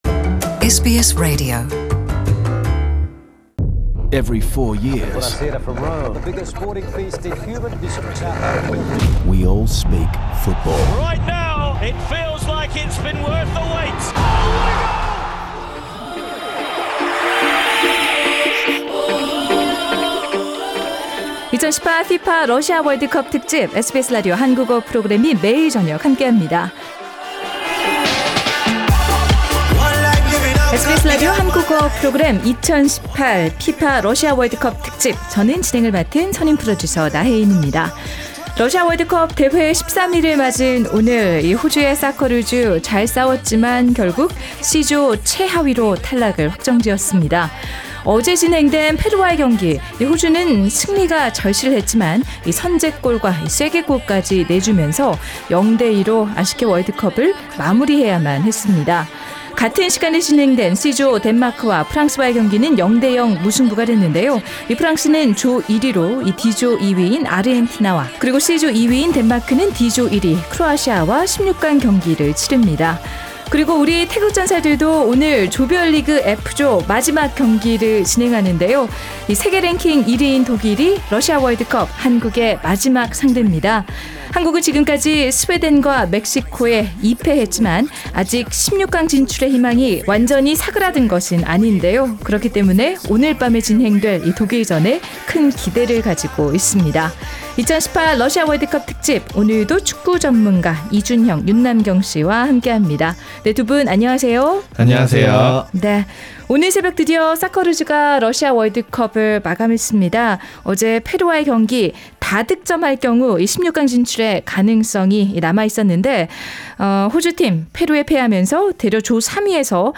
The panel also shares their analysis and prediction about the match Korea v Germany. The full World Cup Panel discussion is available on the podcast above.